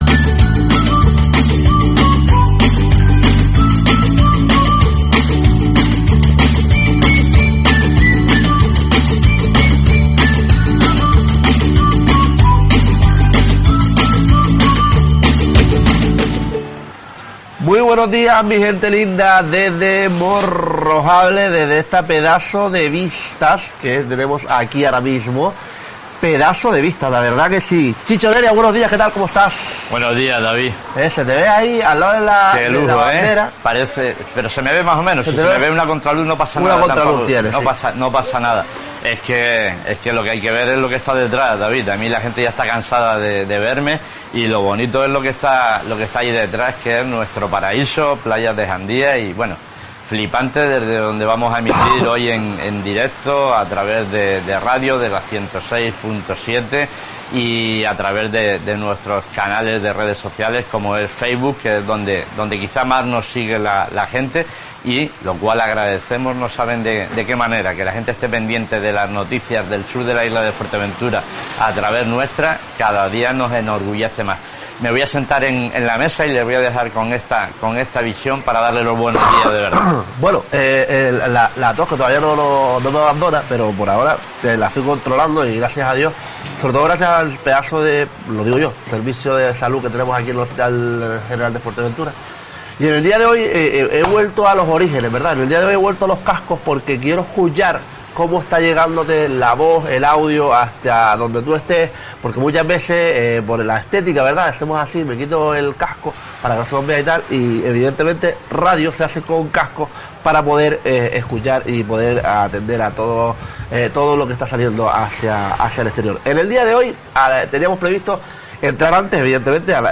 El pasado viernes, día 22 de septiembre, realizamos nuestro programa «El Sur Despierta» desde el Restaurante Laja, en la avenida de Morro Jable.